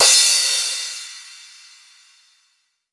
Crashes & Cymbals
MUB1 Crash 002.wav